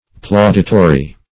Plauditory \Plau"di*to*ry\, a. Applauding; commending.